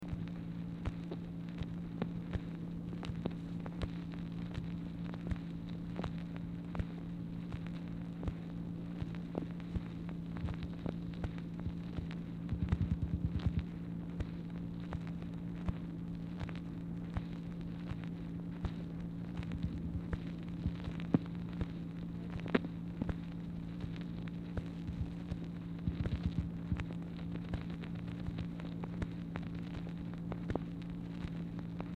Telephone conversation # 2980, sound recording, MACHINE NOISE, 4/10/1964, time unknown | Discover LBJ